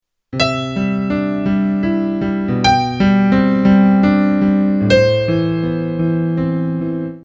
Here you can see, the first melody note is E, which is played with the C chord, because the C triad is C, E, G. I could have selected C or G and it would have sounded equally correct, but this time I decided to use E. In the second bar the chord is G and I have chosen melody note G (from the triad of G, B, and D).
Despite this similarity to an already existing famous piece of music, what is clear is that it’s very easy to listen to and pleasant sounding. It doesn’t sound like someone picking random notes out from the chromatic scale, there’s some structure to it.